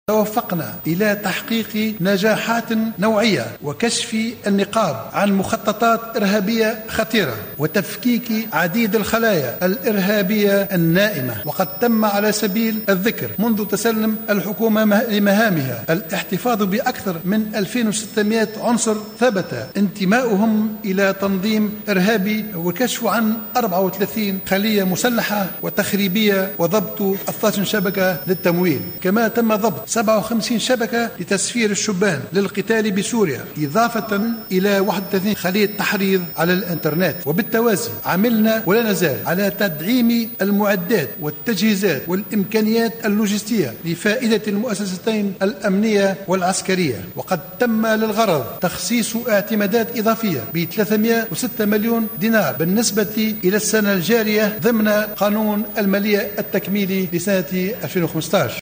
وأبرز الصيد، خلال كلمته في مجلس نواب الشعب بمناسبة النظر في مشروع ميزانية الدولة، أن قوات الأمن نجحت في إيقاف 2600 عنصر ثبت انتماؤهم إلى تنظيم إرهابي، والكشف عن 34 خلية مسلحة وضبط 12 شبكة للتمويل و57 شبكة تسفير إلى بؤر التوتر إضافة إلى 31 خلية تحريض على الانترنيت.